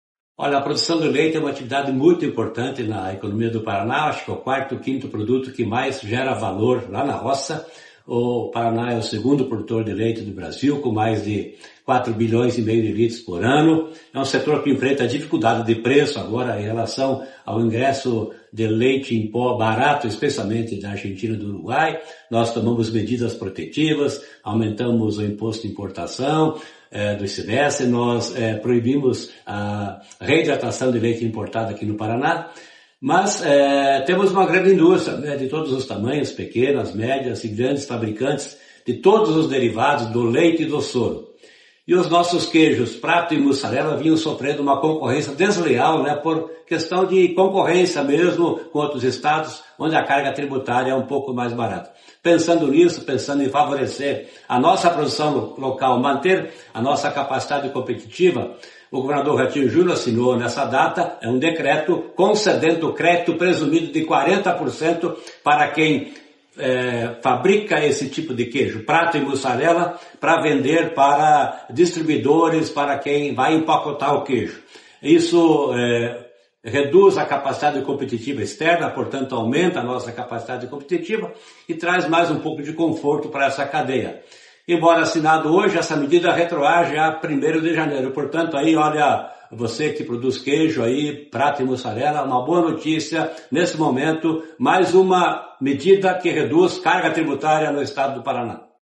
Sonora do secretário Estadual da Fazenda, Norberto Ortigara, sobre o decreto que dá benefício fiscal a produtores de queijo do Estado